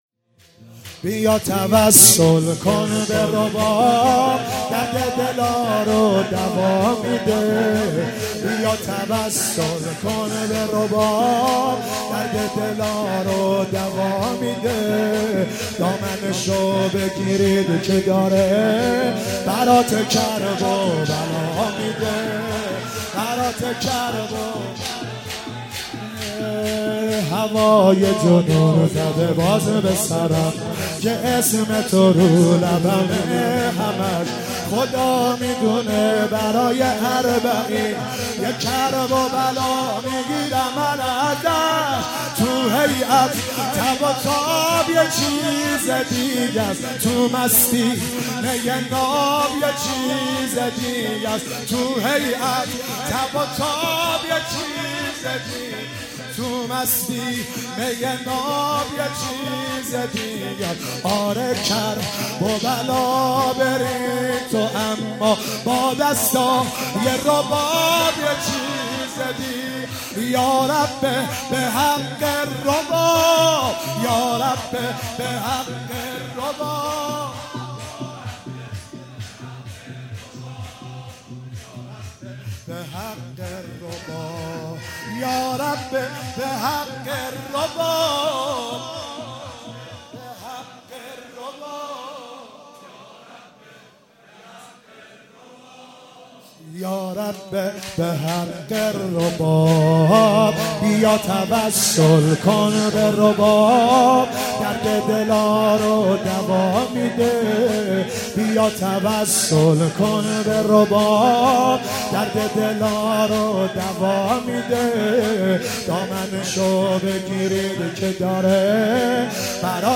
شب هفتم محرم95
شور_ بیا توسل کن به رباب